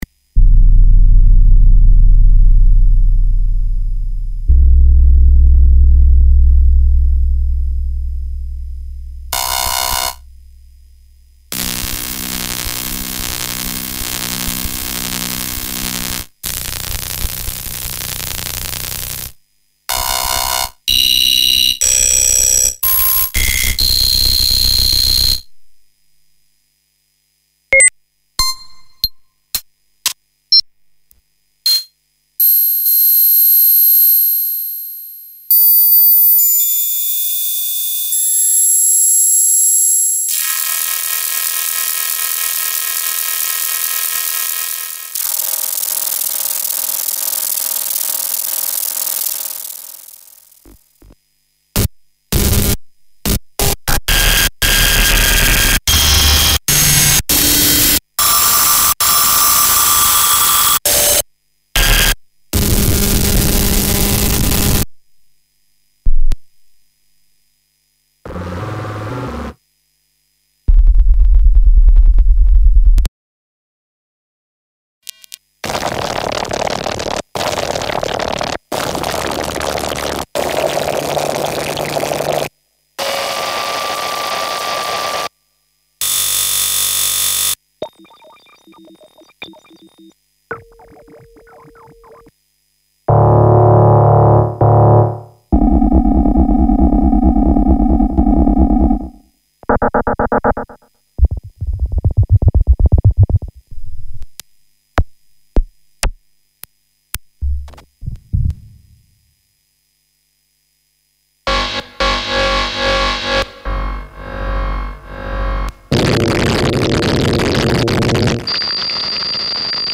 These are forensic, high-intensity impacts designed to inhabit the absolute extremes of the frequency spectrum.
• The Zero-FX Rule: None of these patches use Reverb or Delay. They are bone-dry, surgical, and raw. They rely on the Digitone’s FM engine to provide the air and the grit.
Many units self-evolve or stutter with a life of their own.
Suitable for Minimal Techno, Noise, IDM, Glitch, Drone and more.